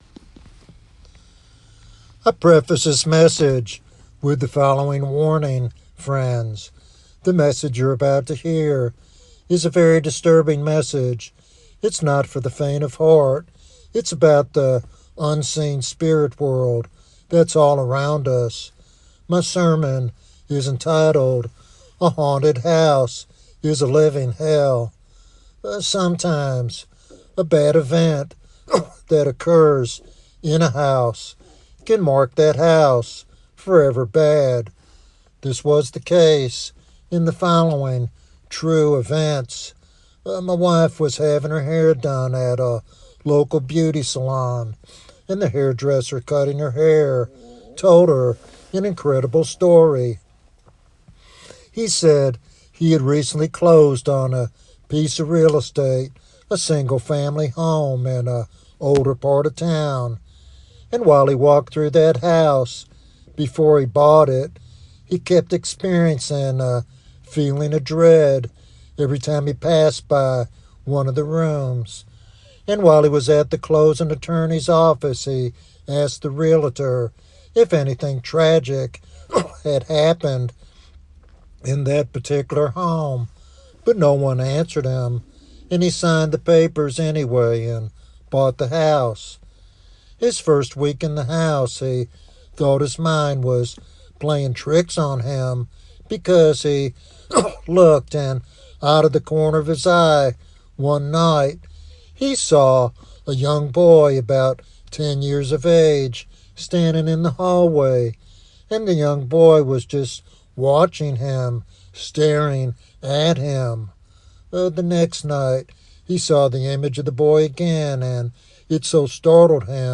In this compelling and sobering sermon